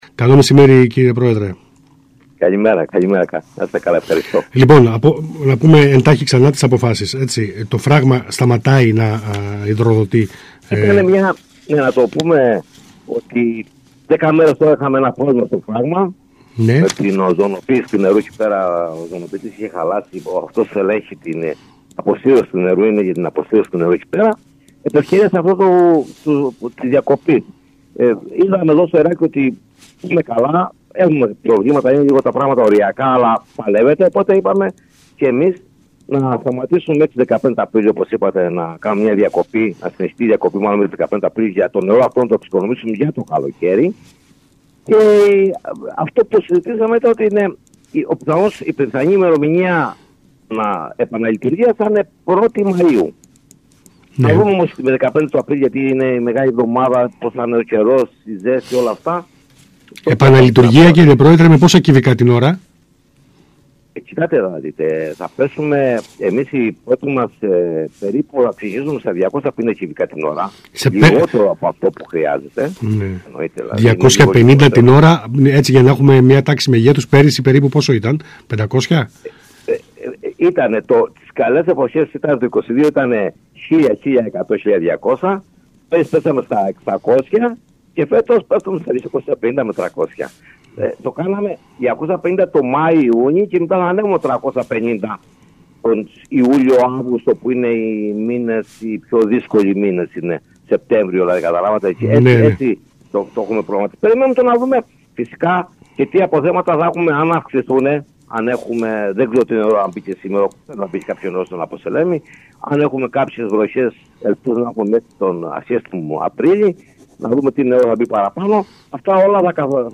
μιλώντας το μεσημέρι της Τετάρτης 19 Μαρτίου στον ΣΚΑΙ Κρήτης 92.1.